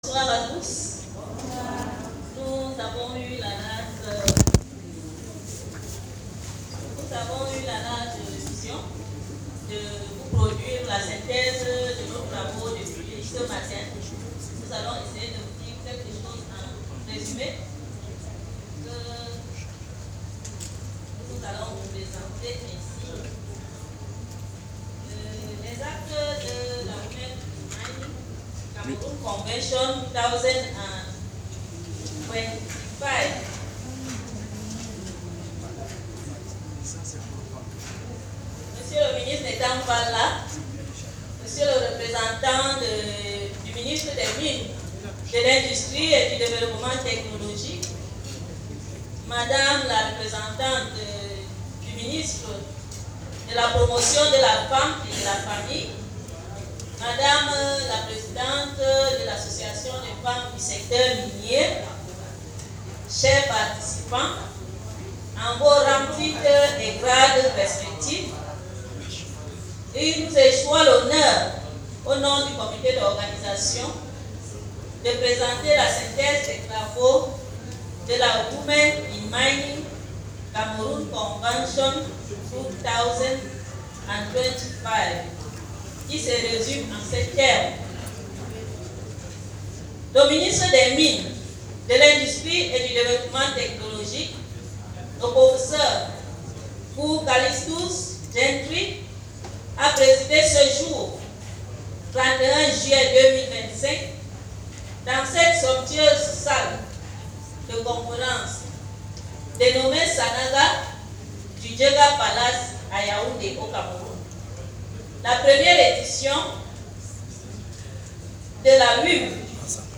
Le Pr Fuh Calistus Gentry, représentant le Ministère de tutelle, ouvre les travaux en dévoilant les grandes lignes d’une stratégie nationale. Son discours évoque la mutation réglementaire, les perspectives économiques, et l’importance d’une synergie entre innovation, territoire et engagement citoyen.